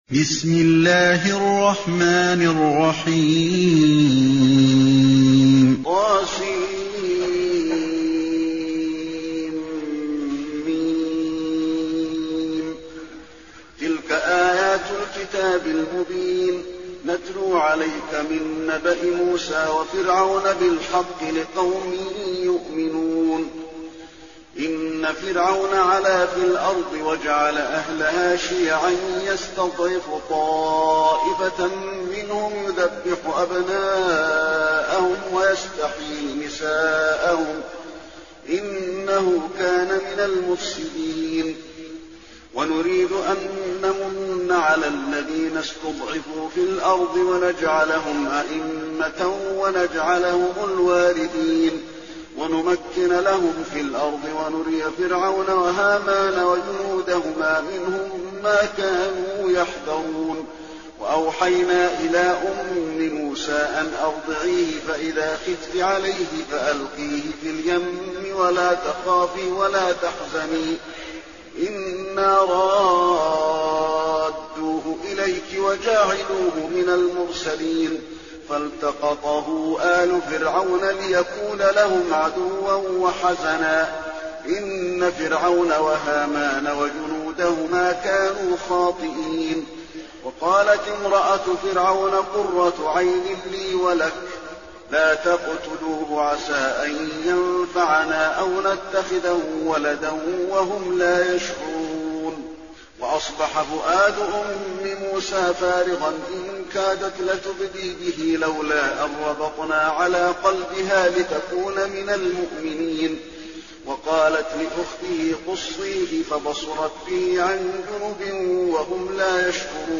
المكان: المسجد النبوي القصص